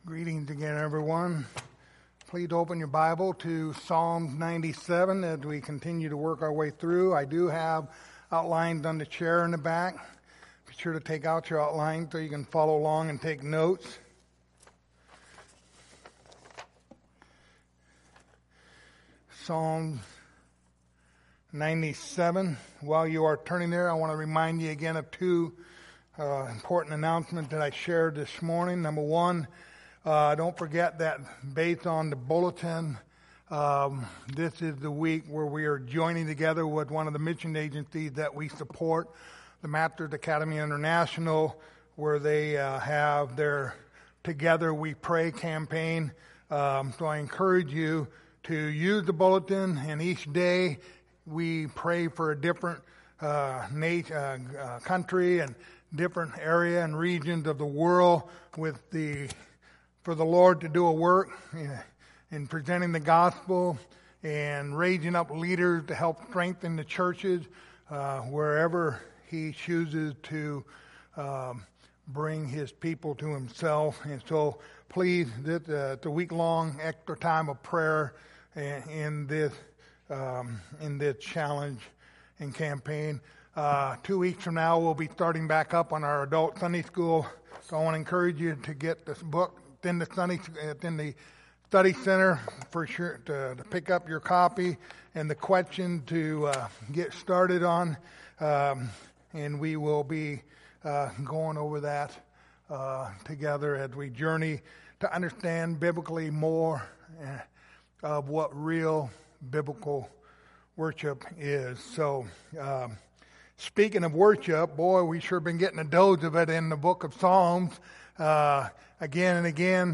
The book of Psalms Passage: Psalms 97:1-12 Service Type: Sunday Evening Topics